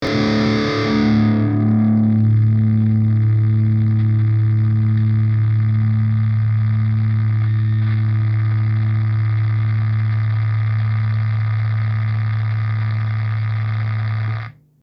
Ich habe, egal welches Rig ich nutze ein unangenehmes recht lautes Knistern im Hintergrund (Datei angehängt). Bei Rigs mit mehr Gain kommt es mehr zur Geltung.
Was auffällig ist, das Knistern wird stärker umso höher der Tone Regler gedreht ist.